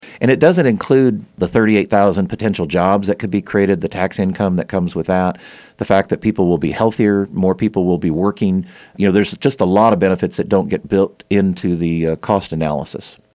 Lt. Gov. Lynn Rogers in-studio for KMAN's In Focus, Wednesday, April 3, 2019.